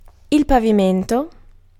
Ääntäminen
Synonyymit parterre humus Ääntäminen France: IPA: /sɔl/ Haettu sana löytyi näillä lähdekielillä: ranska Käännös Ääninäyte Substantiivit 1. suolo {m} Muut/tuntemattomat 2. terreno {m} 3. pavimento {m} Suku: m .